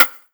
taiko-normal-hitwhistle.wav